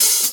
Dusty Open Hat 01wav.wav